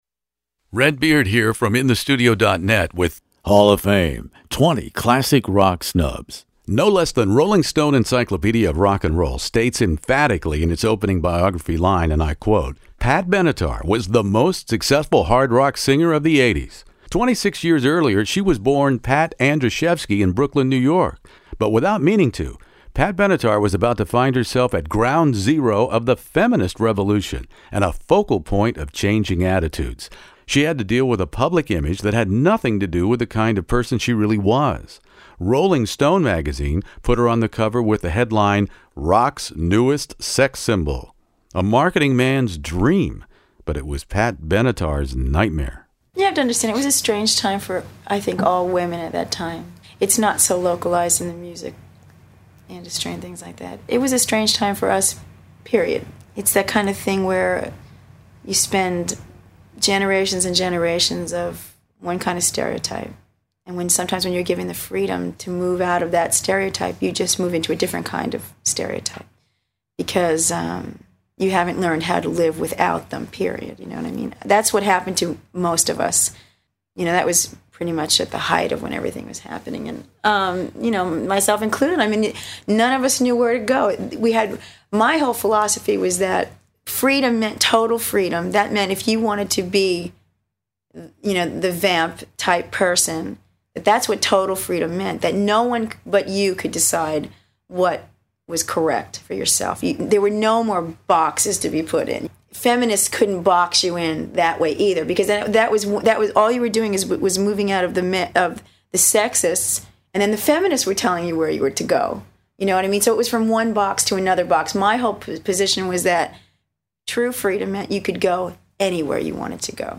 More of your favorite classic rock interviews from stars who have been omitted, prevented, blocked, denied, ignored, blacklisted, jilted, overlooked, underloved, and still not invited to the party at the Rock and Roll Hall of Fame. Time will tell if recent changes at the very top of the Rock Hall’s entrenched leadership will mitigate any of the induction controversies.